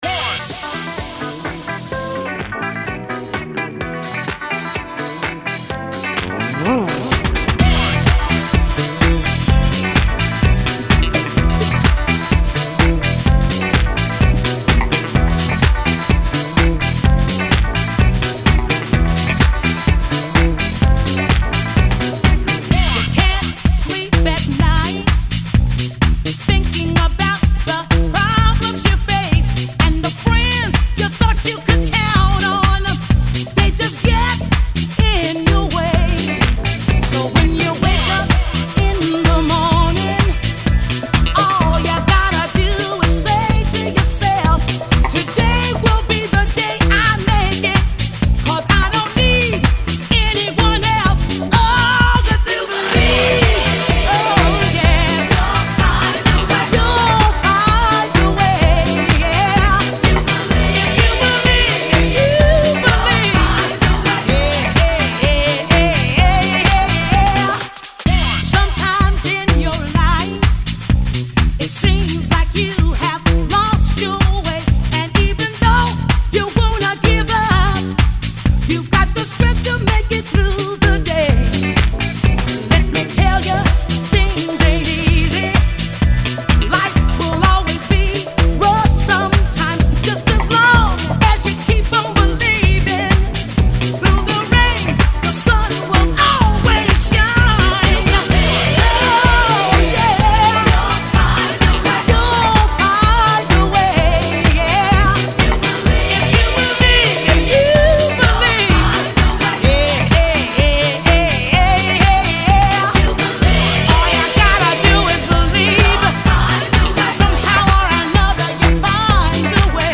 is an American R&B and dance music singer.